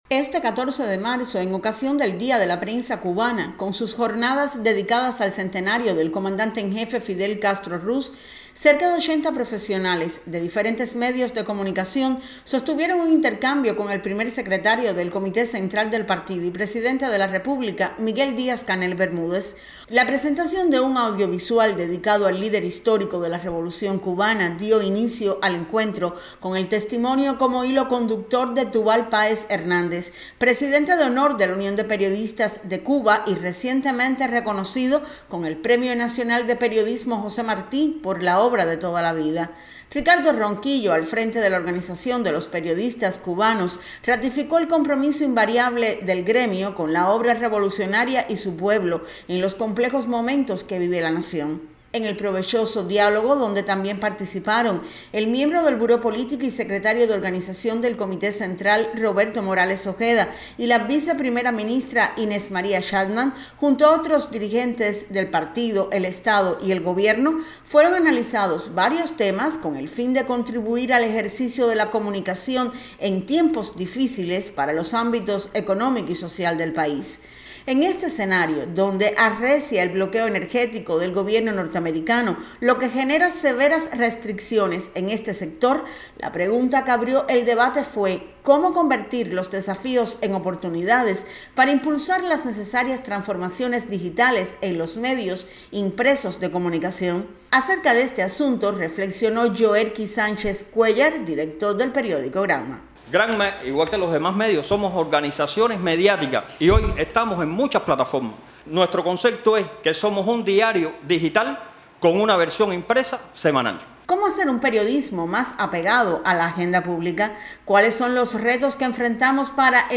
Felicita el Primer Secretario del Comité Central del Partido y Presidente de la República, Miguel Díaz-Canel Bermúdez, a los trabajadores de los medios de comunicación social en el Día de la Prensa Cuba. Sostiene encuentro con representes de la prensa impresa, radial, televisiva, digital y de agencias.